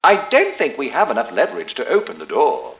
1 channel
mission_voice_t6bd010.mp3